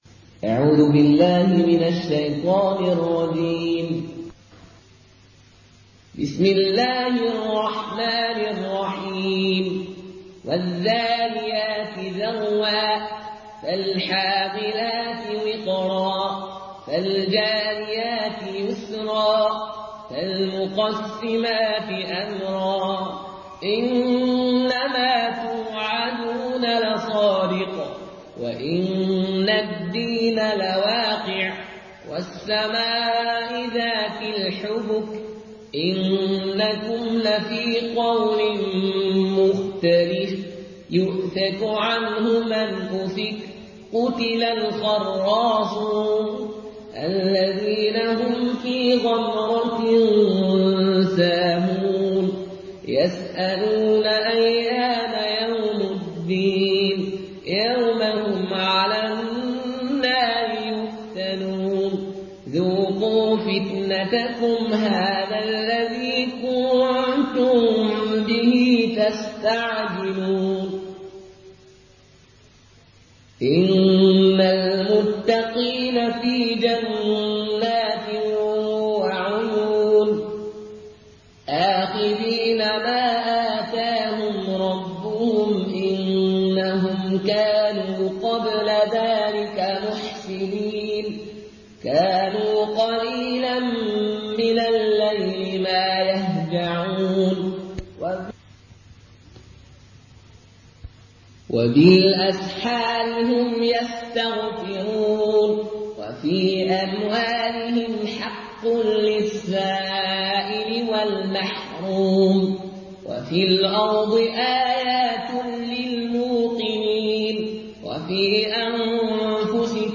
Murattal